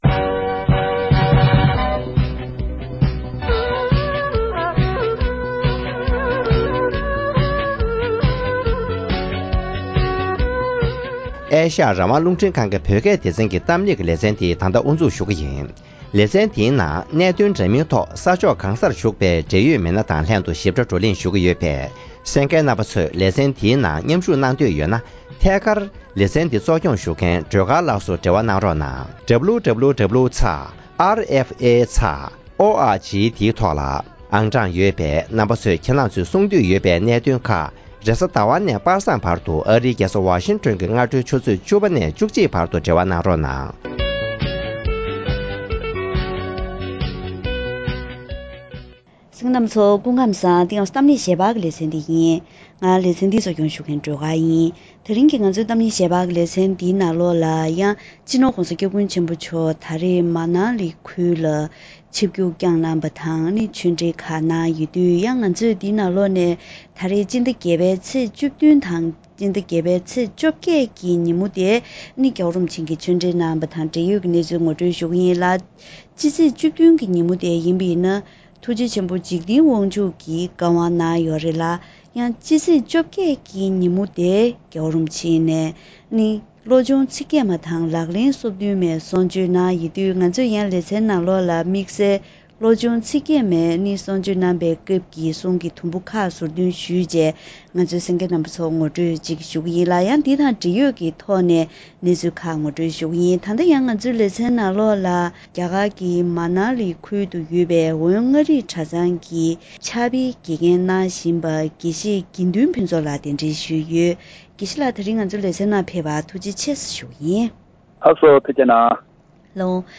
སྤྱི་ནོར་༧གོང་ས་སྐྱབས་མགོན་ཆེན་པོ་མཆོག་རྒྱ་གར་གྱི་མཱ་ནཱ་ལི་ཁུལ་དུ་ཚེས་༡༨ཉིན་གསུང་ཆོས་གནང་སྐབས།